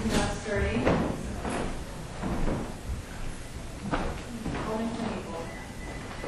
I have left my recorder on a table in the main room of the museum.  You can hear we are all heading down stairs into the basement and I think the EVP says “GO” as we are exiting the room.